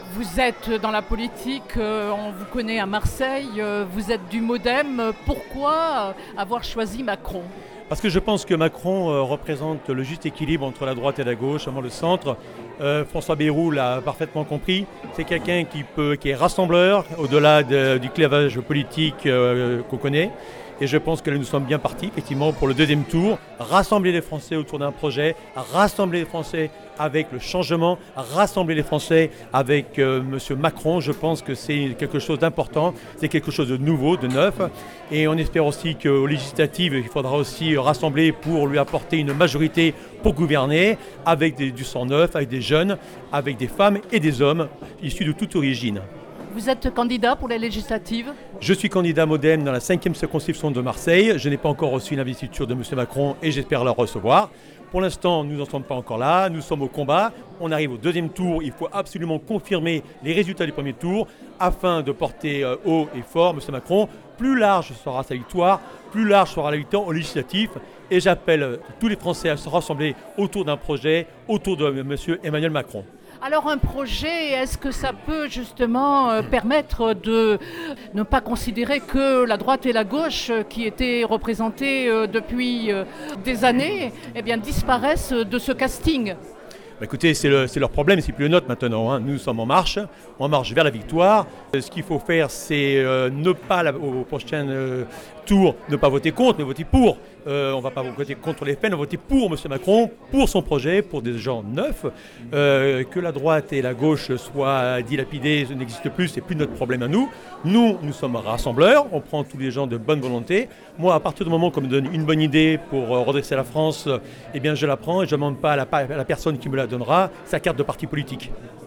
Marseille – Au QG d’En Marche la victoire est savourée: réactions